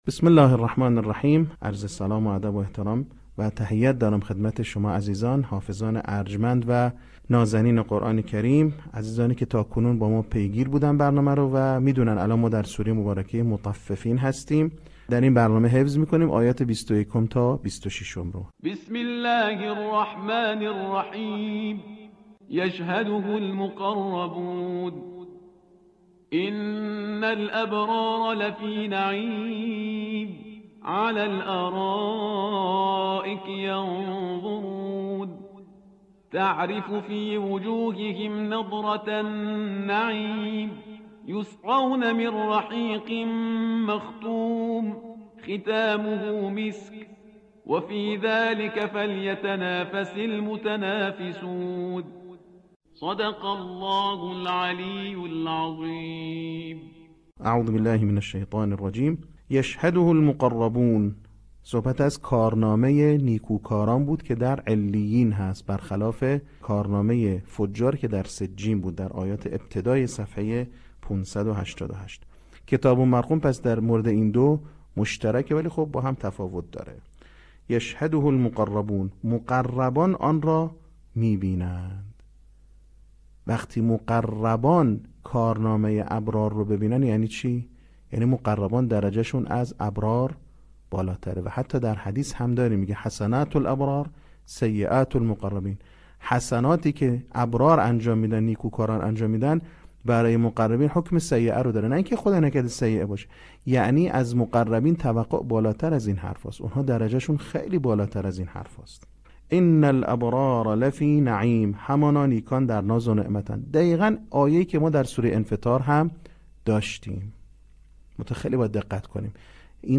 صوت | بخش چهارم آموزش حفظ سوره مطففین